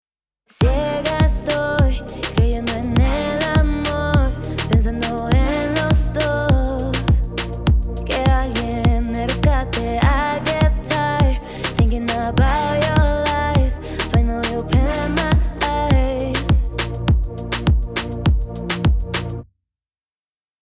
バックトラックを含めた状態で、ヴォコーダーなしの状態と、バックコーラスとしてのヴォコーダーを加えたバージョンを聞いてみます。
完成形（ヴォコーダーなし）
mix_vocoder_off.mp3